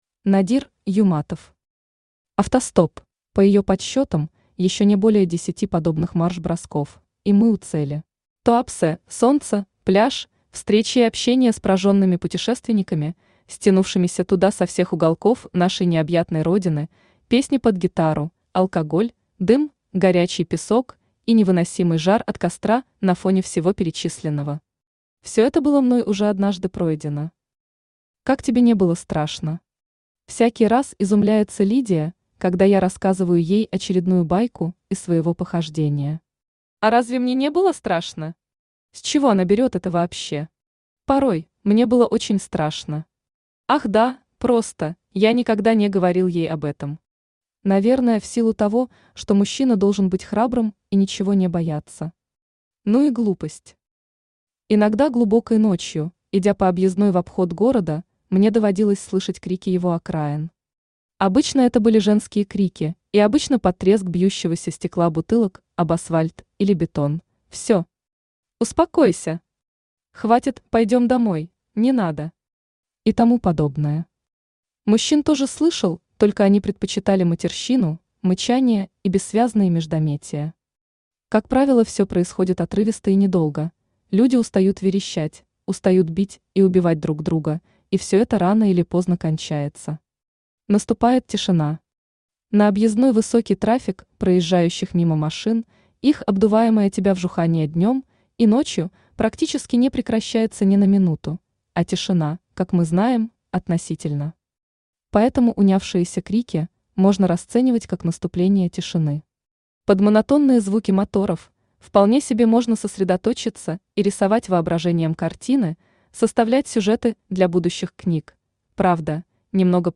Aудиокнига Автостоп Автор Надир Юматов Читает аудиокнигу Авточтец ЛитРес.